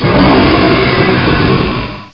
Add all new cries
cry_not_zekrom.aif